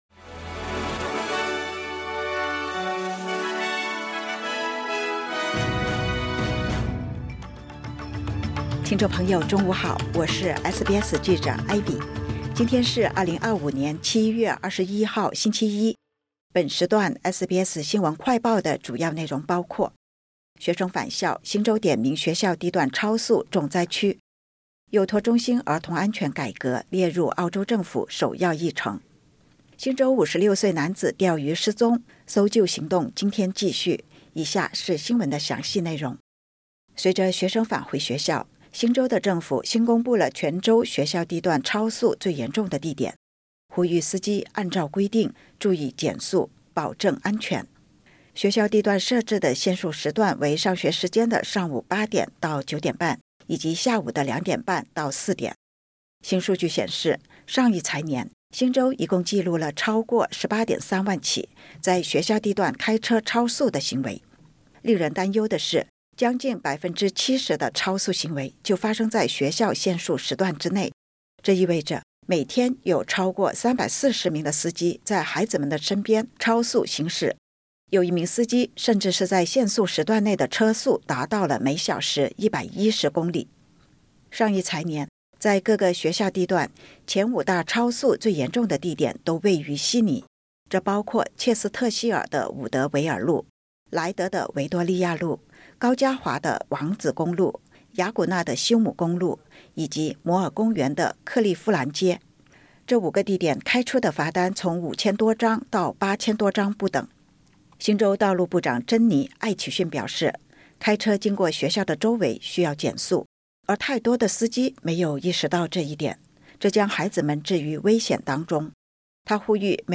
【SBS新闻快报】学生返校在即 新州点名学校地段超速“重灾区”